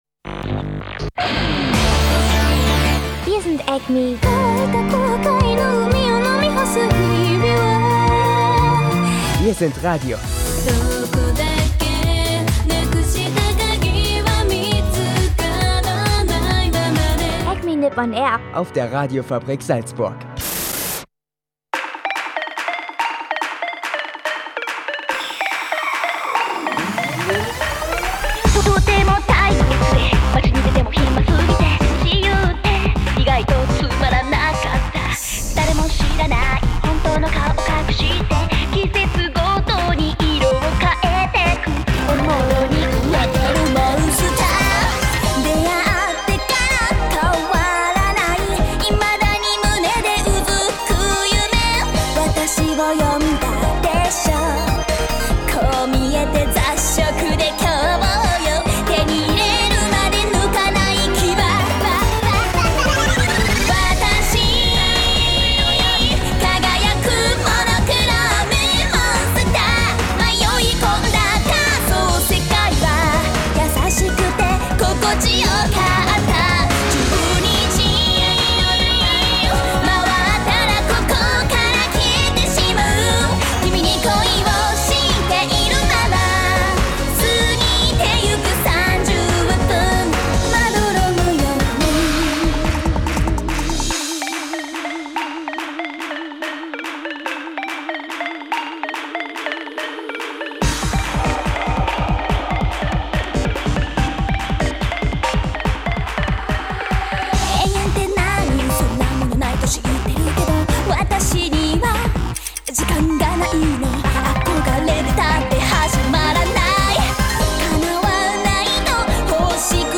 Dazu natürlich Musik aus Animes und Japan-Nachrichten und Wetter.